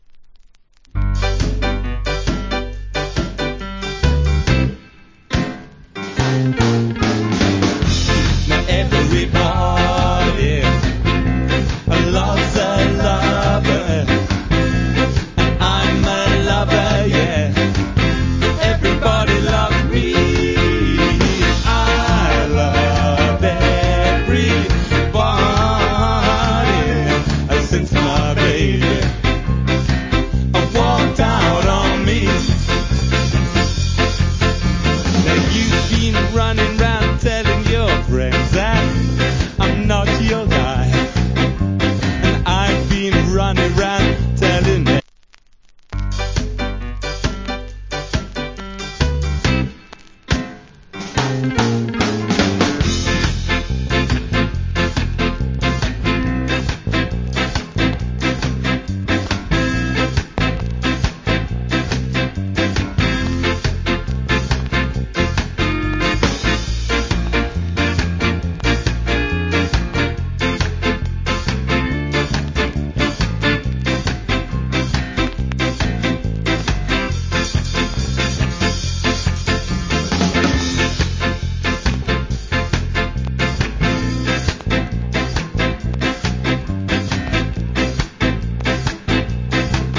Good Ska Vocal